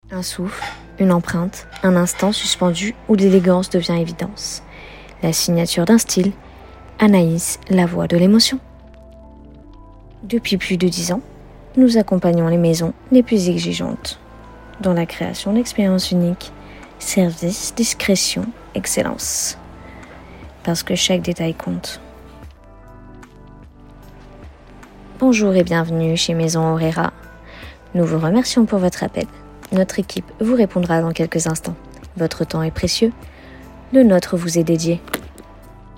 Voix off
🎙 Voix-off posée, douce et articulée, idéale pour :
🎧 Bande démo disponible